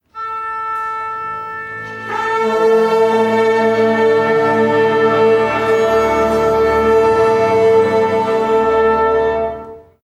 Preparación de una orquesta
afinar
orquesta
Sonidos: Acciones humanas